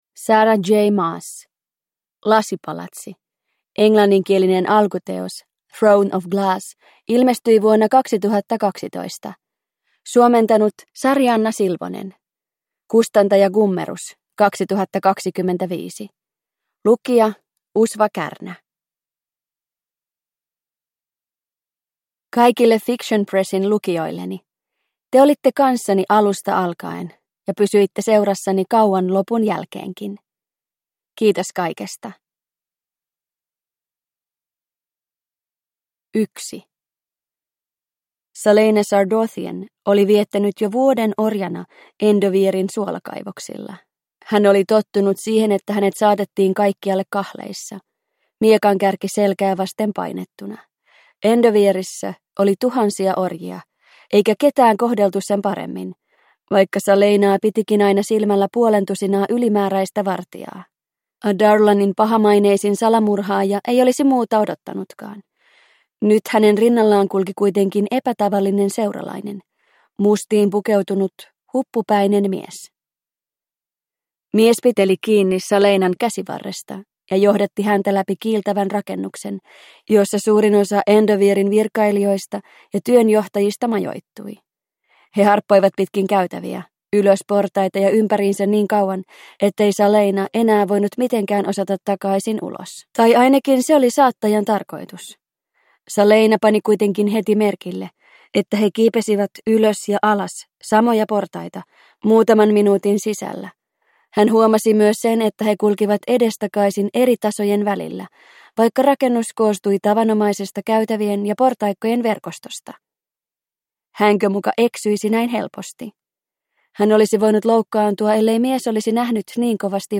Throne of Glass - Lasipalatsi – Ljudbok